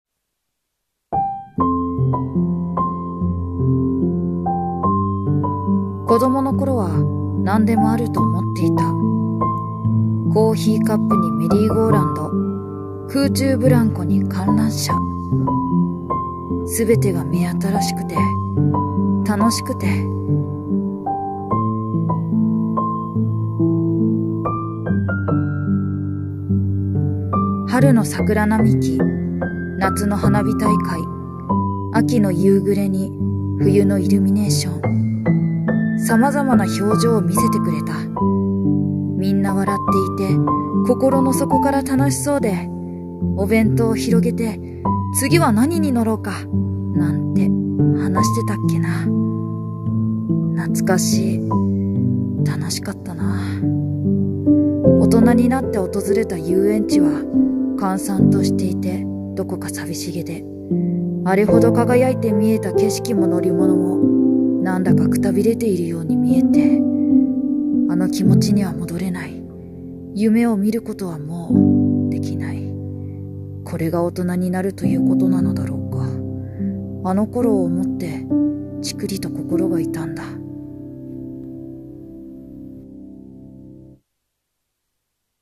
】【声劇】黄昏の遊園地。